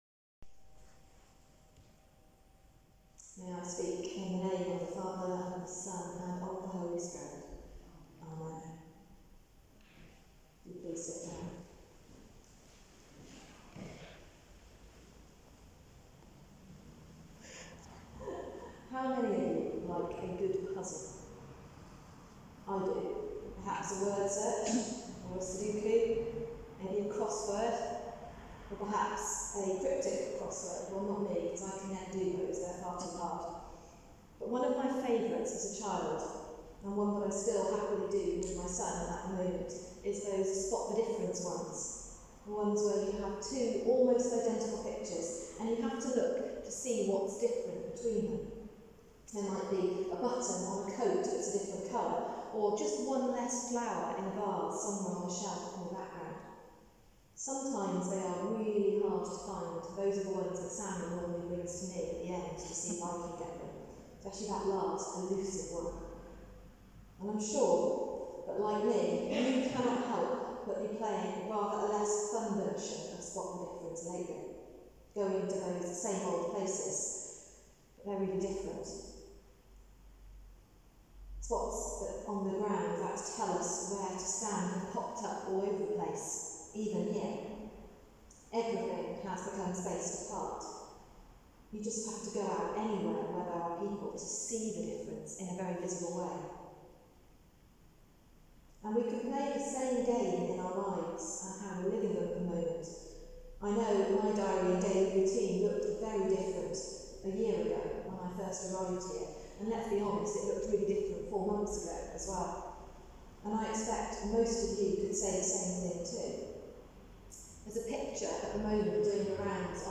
Sermon: The Kingdom of God Has Come Near | St Paul + St Stephen Gloucester